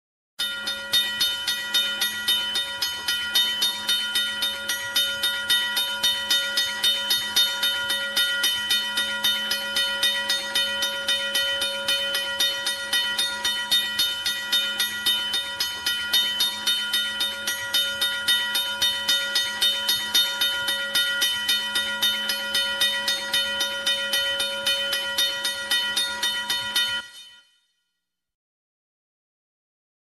Звук корабельной тревоги и колокола